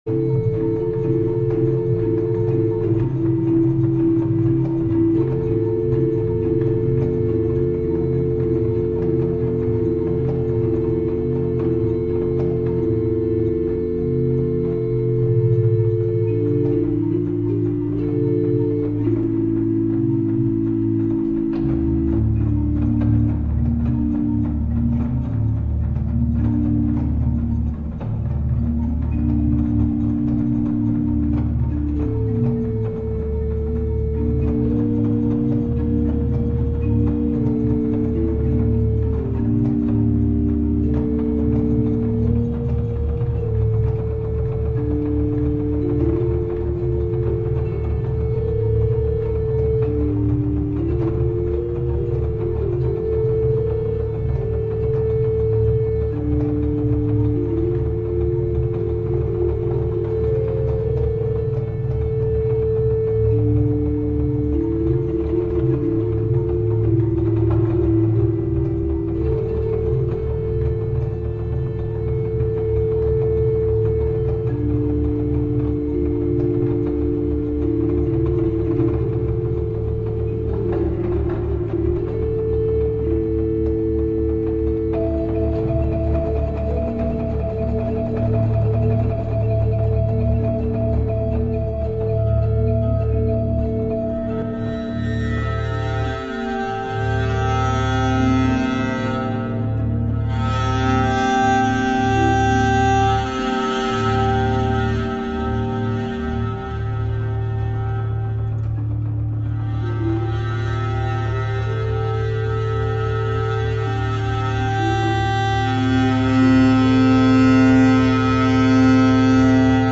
Klanginstallationen - Ausschnitte im mp3-Format
Klanginstallation (Dauer 5:00min)